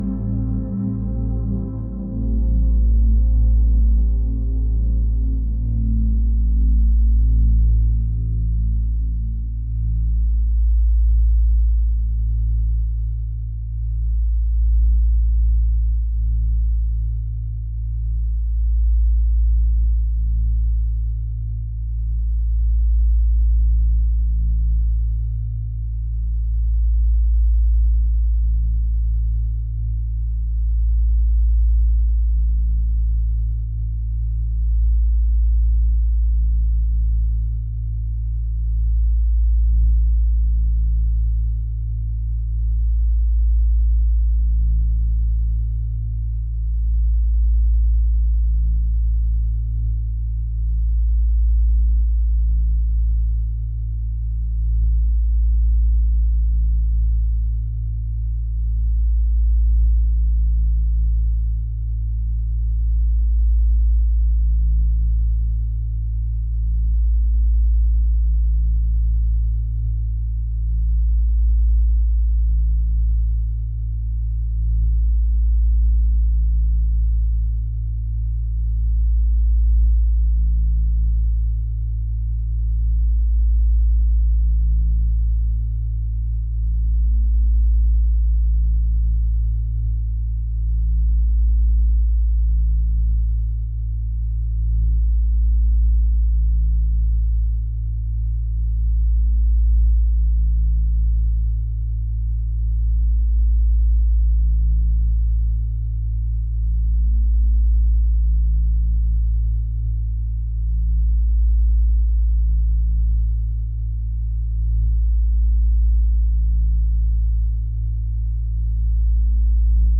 electronic | atmospheric